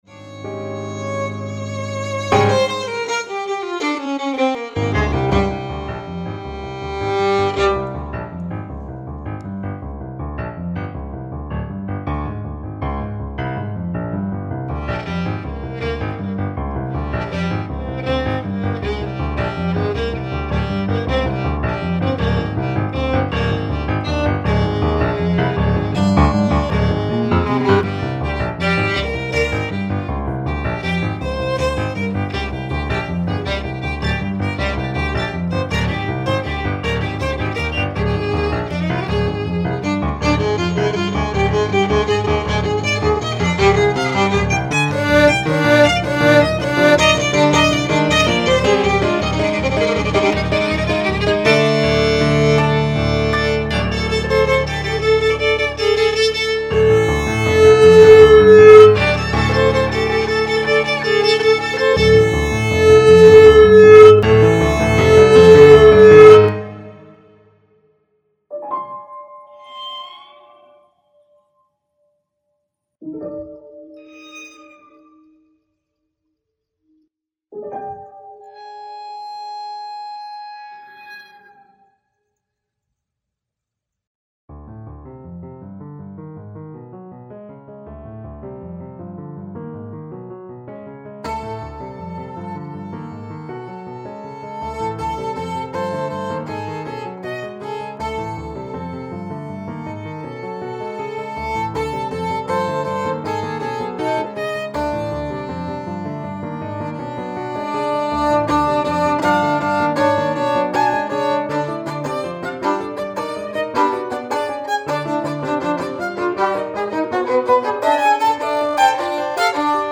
violin & piano